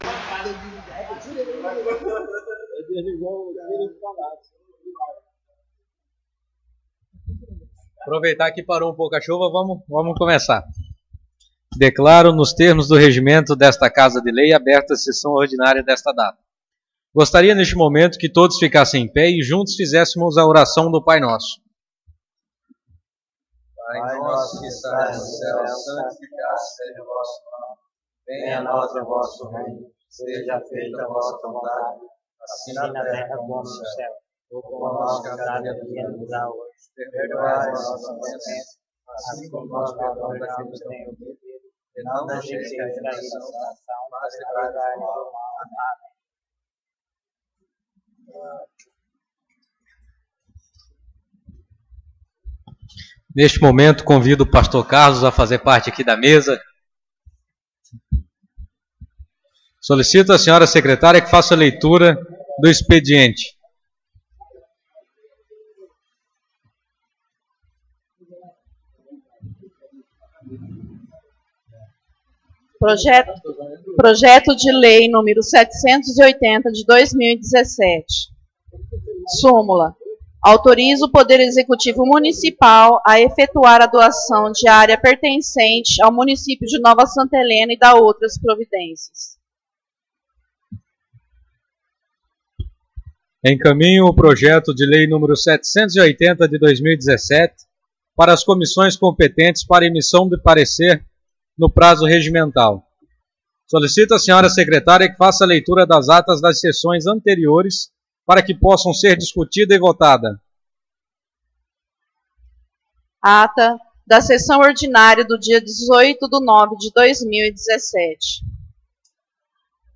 Sessão Ordinária 02/10/2017